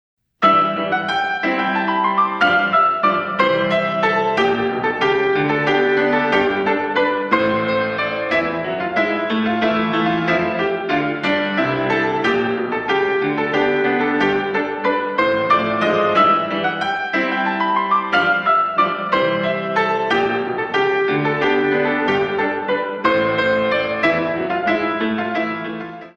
128 Counts